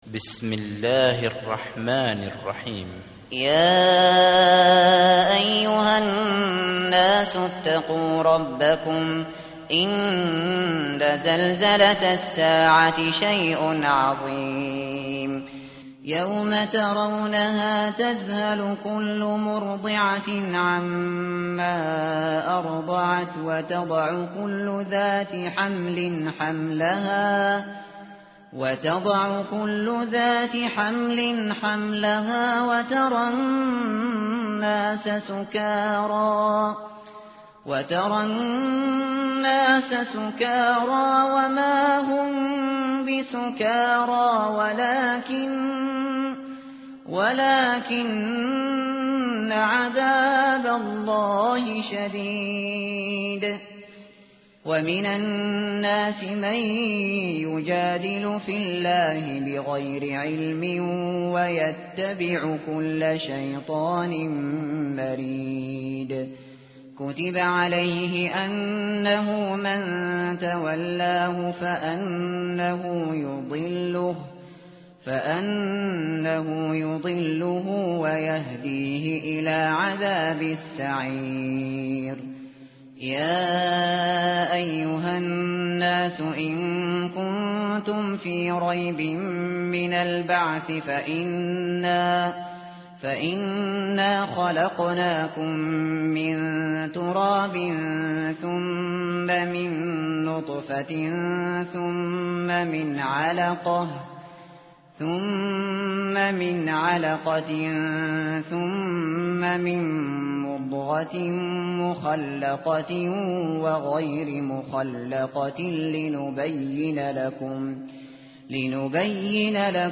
tartil_shateri_page_332.mp3